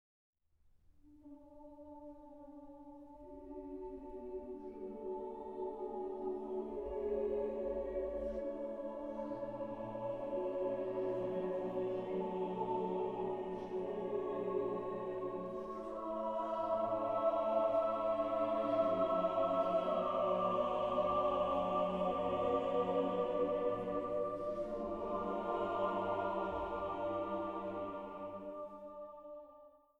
für zwölfstimmigen gemischten Chor a cappella